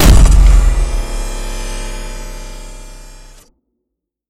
plasma-explosion-01.ogg